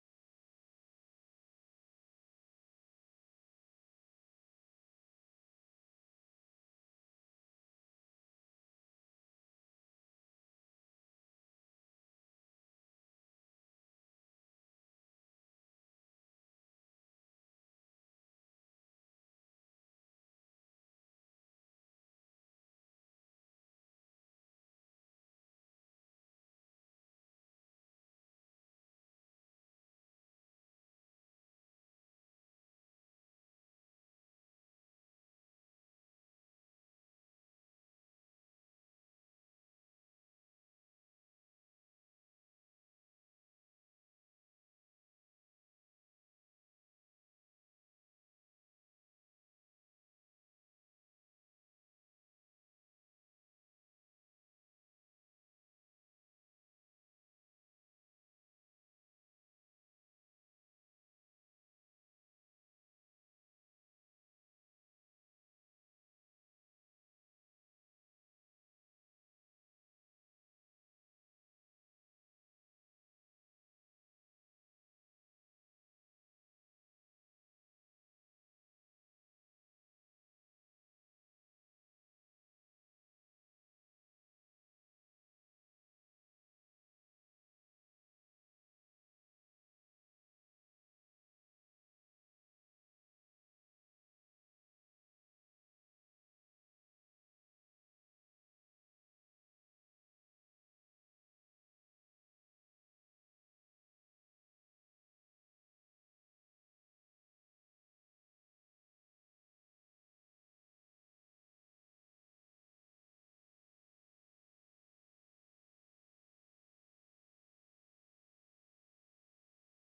Söndagsgudstjänst Arkiv - Saronkyrkan